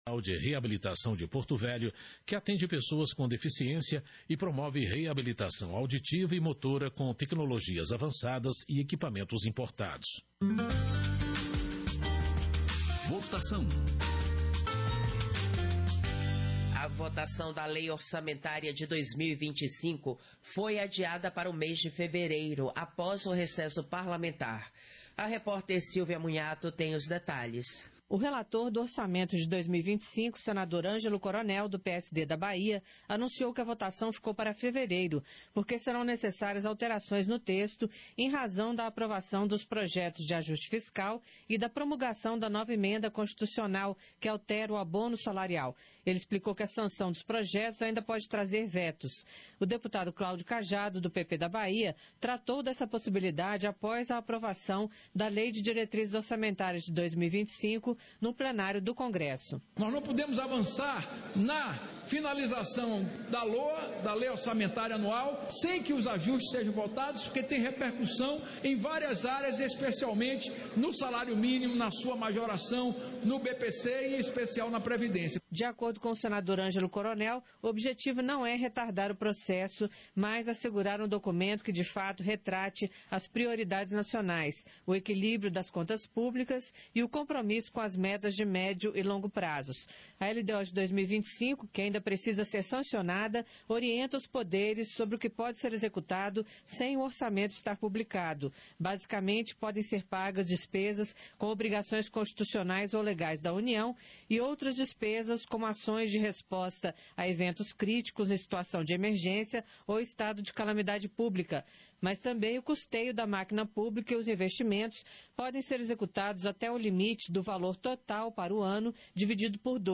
Audio da sessão ordinaria 42-2024